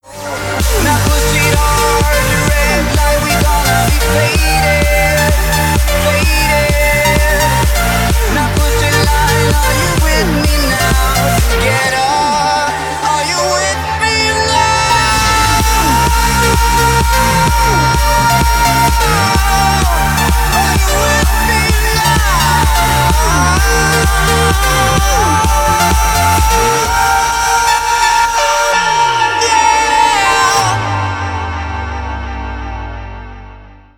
• Качество: 320, Stereo
club